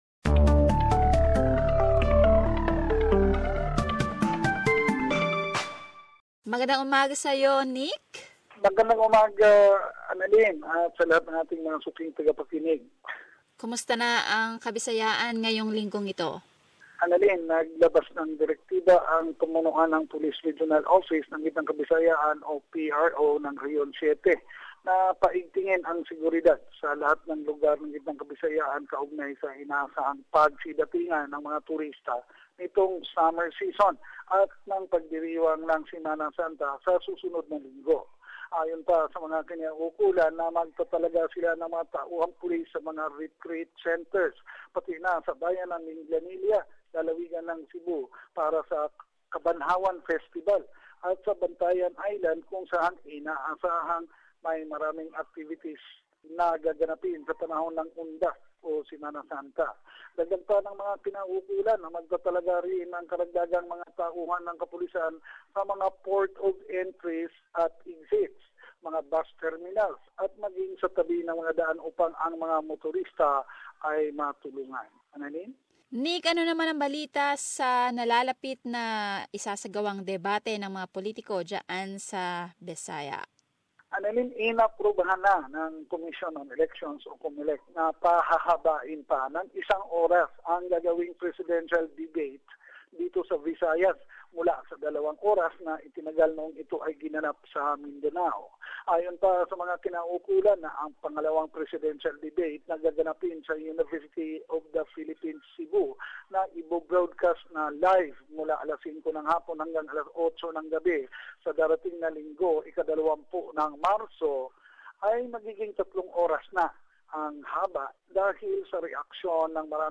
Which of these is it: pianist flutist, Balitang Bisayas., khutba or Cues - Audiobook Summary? Balitang Bisayas.